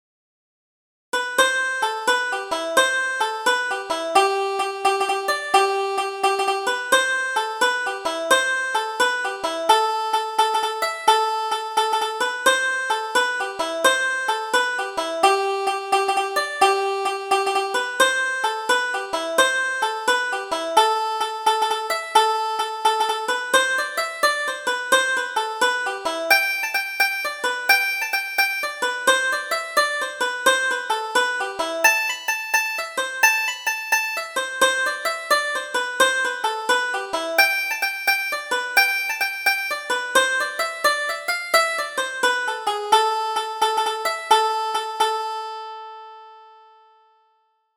Double Jig: Tie the Petticoat Tighter